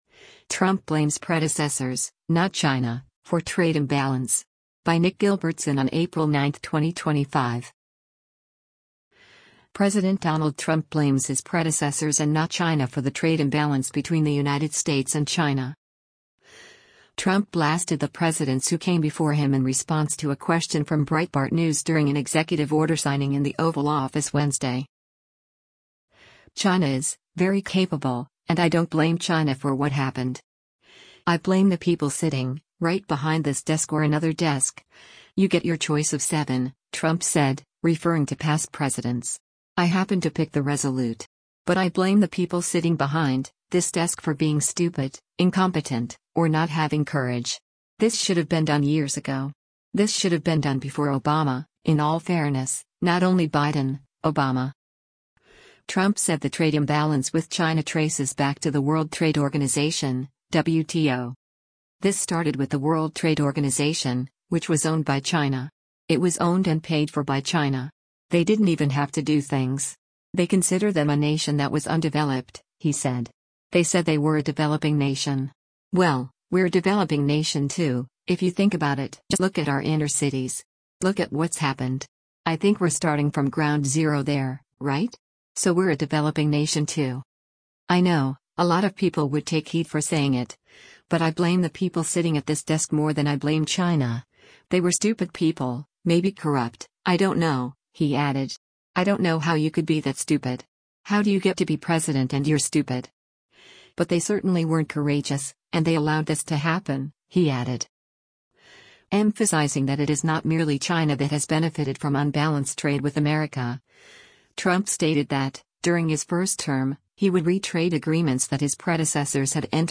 Trump blasted the presidents who came before him in response to a question from Breitbart News during an executive order signing in the Oval Office Wednesday.